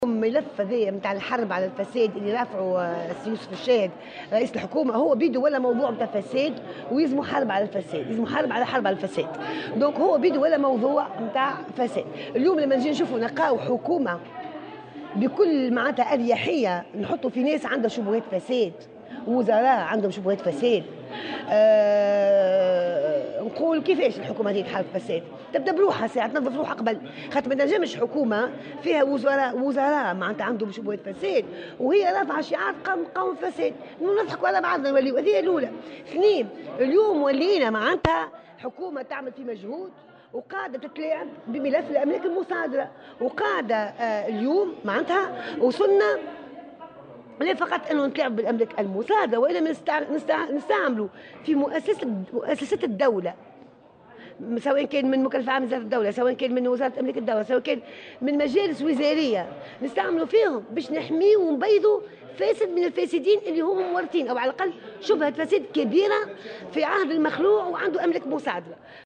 وقالت عبو في تصريح لمراسلة "الجوهرة أف أم" إنه لا يمكن لحكومة تضم في صفوفها وزراء تحوم حولهم شبهات فساد أن تحارب الفساد.